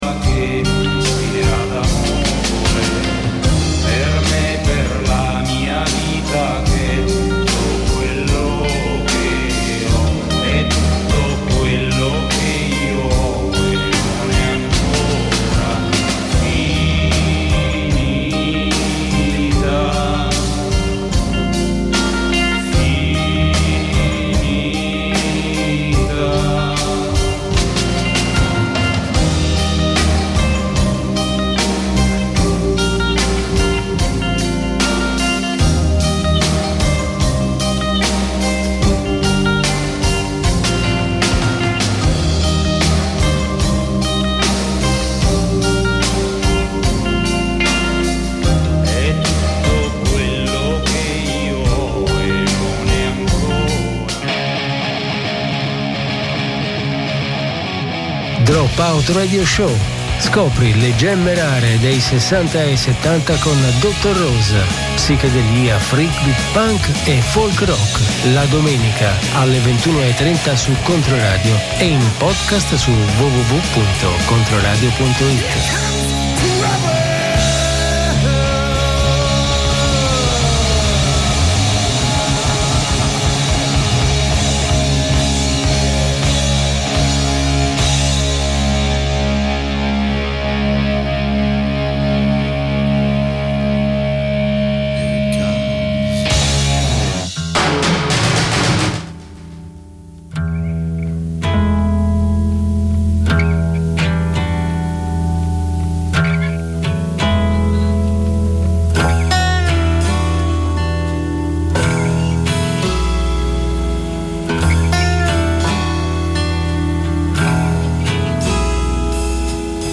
Scopri le gemme rare degli anni '60 e '70: psichedelia, freakbeat, punk e folk rock.